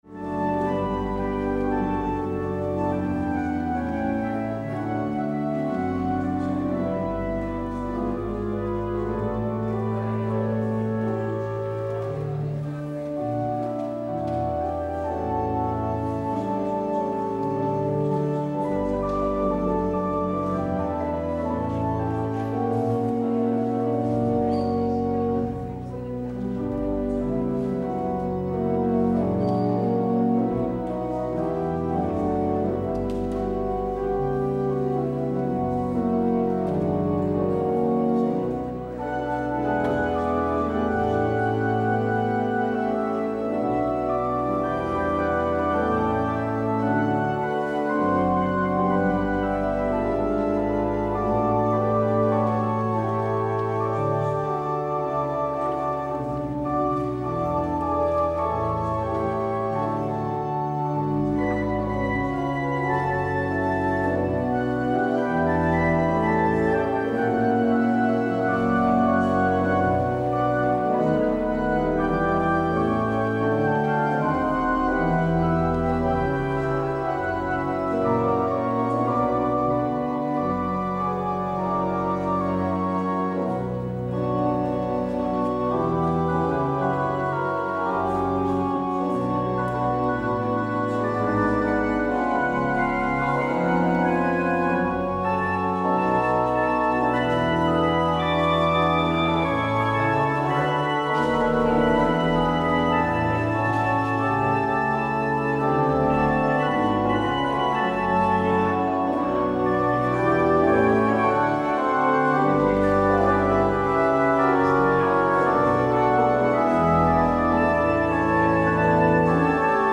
 Beluister deze kerkdienst hier: Alle-Dag-Kerk 9 juli 2025 Alle-Dag-Kerk https